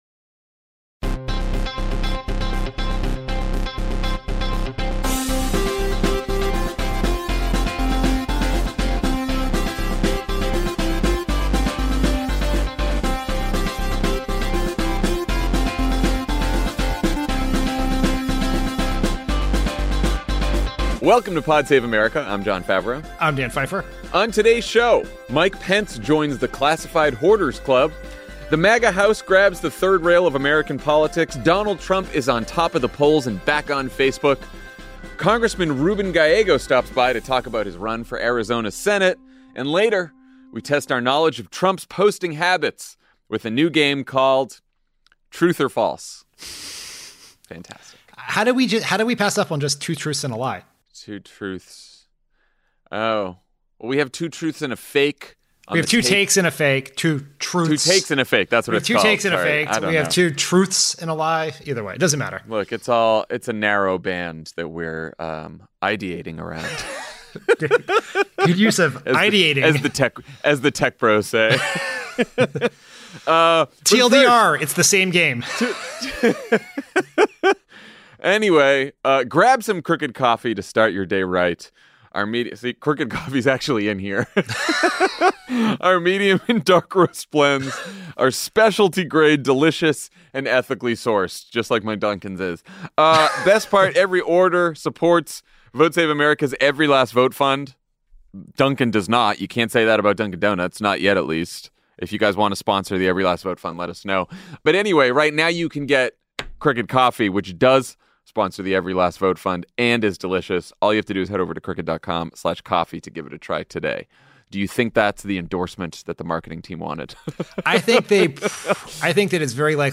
Donald Trump is on top of the polls and back on Facebook. Congressman Ruben Gallego stops by to talk about his run for Arizona Senate.